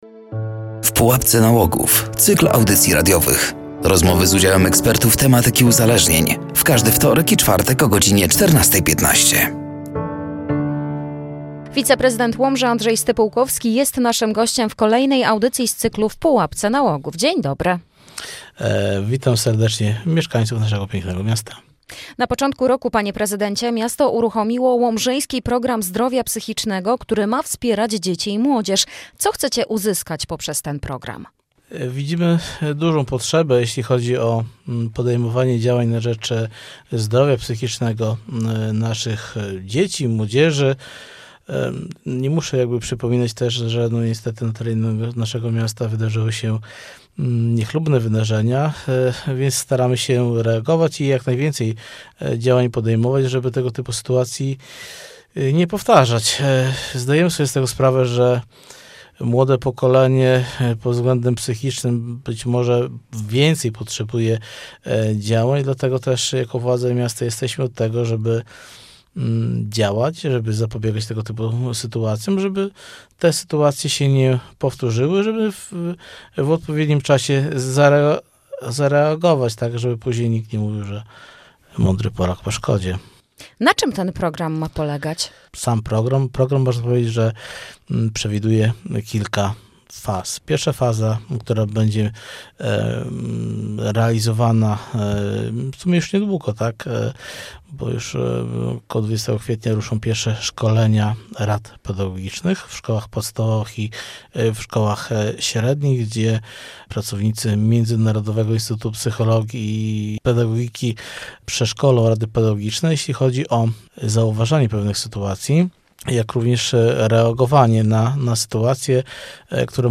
Od lutego br. w Łomży realizowany jest Program Zdrowia Psychicznego skierowany do dzieci i młodzieży. O skali problemu młodych ludzi, założeniach projektu , a także formach pomocy psychologicznej dla mieszkańców miasta mówił na antenie Radia Nadzieja wiceprezydent Łomży Andrzej Stypułkowski.
“W pułapce nałogów” cykl audycji radiowych poświęconych profilaktyce uzależnień wśród dzieci i młodzieży. Rozmowy z udziałem ekspertów tematyki uzależnień.